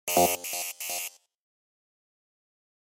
Звуки ввода пароля
Звук невірного введення пароля